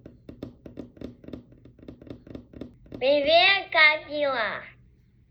vad-hello-stereo-44100.wav